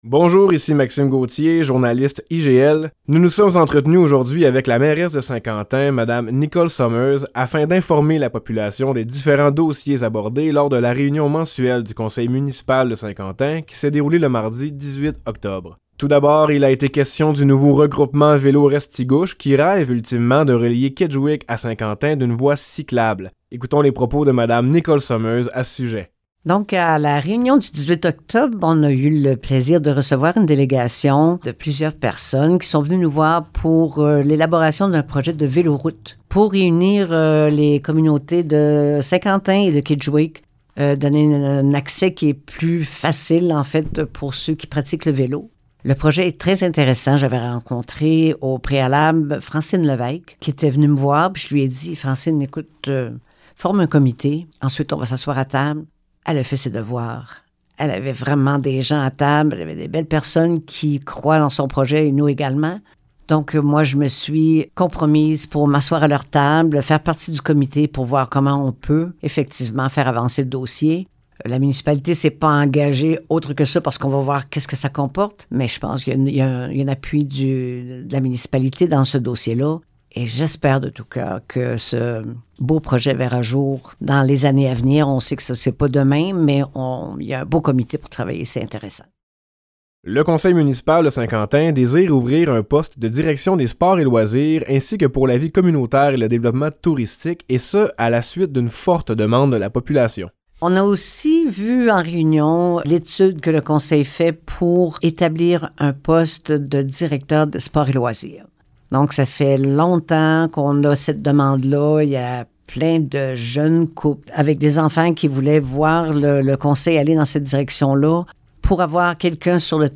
La mairesse de Saint-Quentin, madame Nicole Somers, était présente dans nos studios ce matin pour aborder les grandes lignes de la plus récente réunion mensuelle de Saint-Quentin, le 18 octobre dernier.